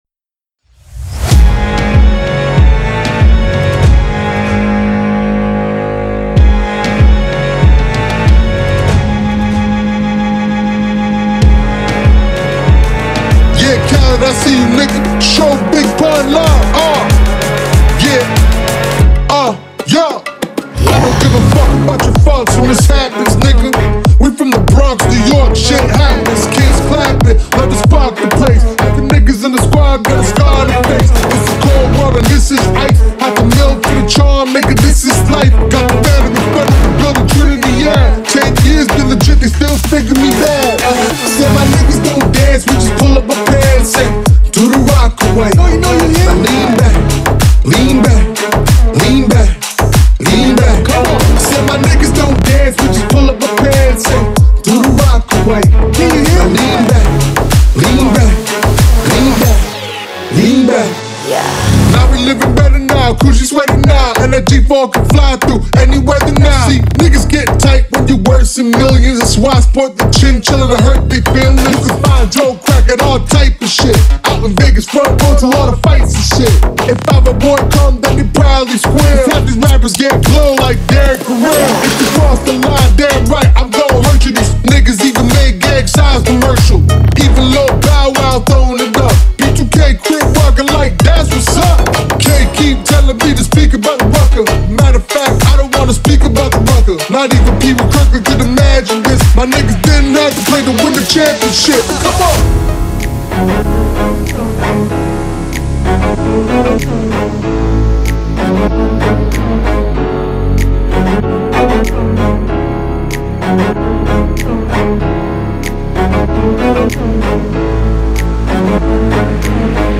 • Жанр: Electronic, Hip-Hop